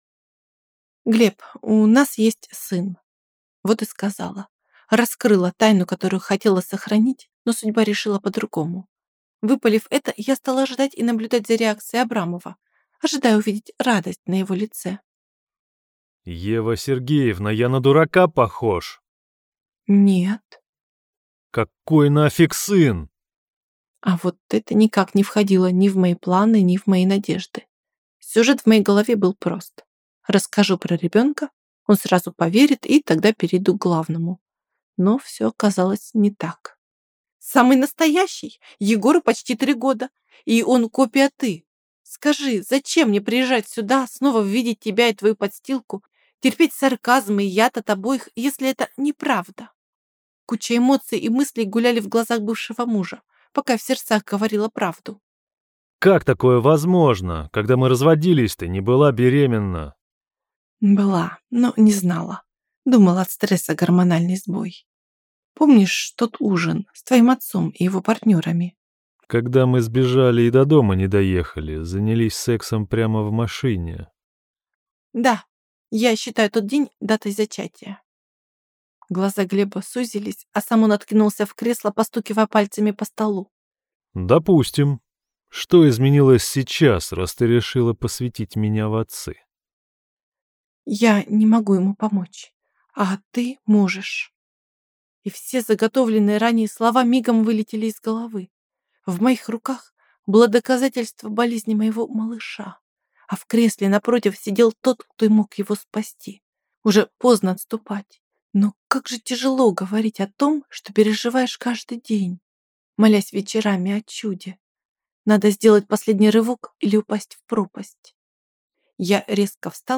Аудиокнига Обретая нас | Библиотека аудиокниг